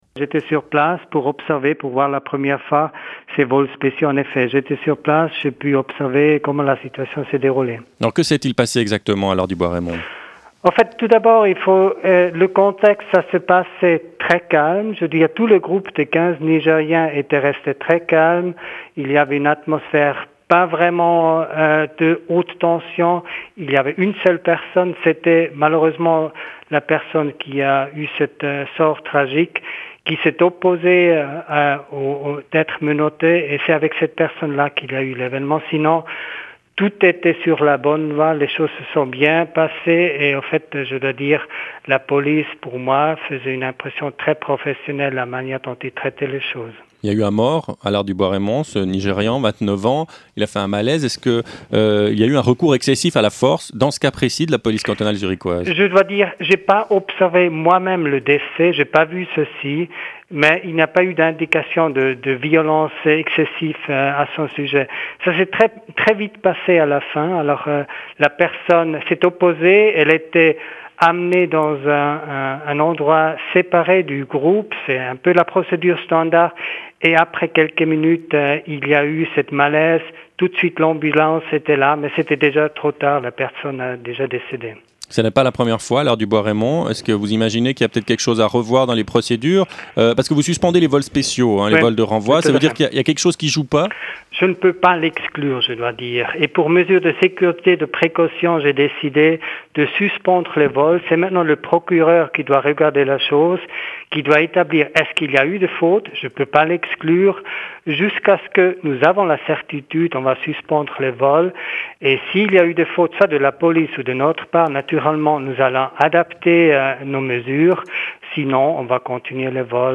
Alard du Bois-Reymond, directeur de l’Office fédéral des migrations